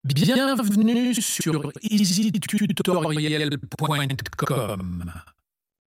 3. Changer le tempo de l'audio
audio-change-tempo-lent.mp3